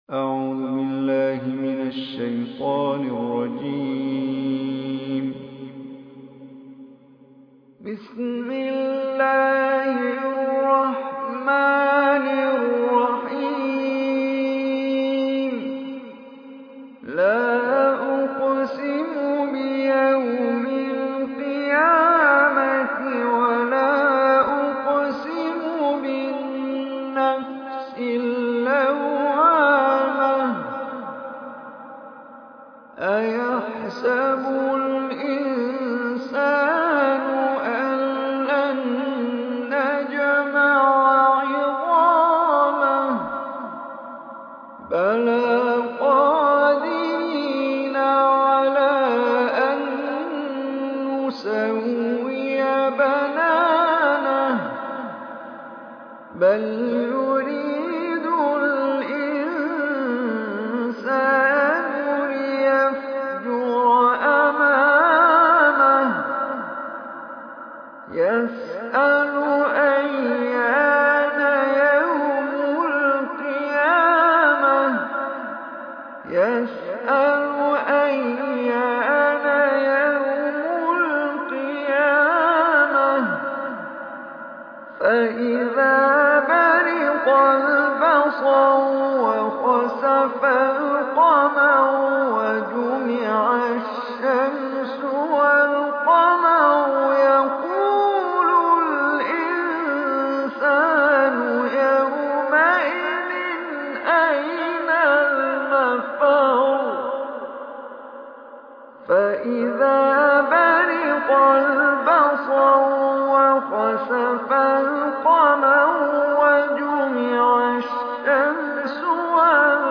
Surah Qiyamah MP3 Recitation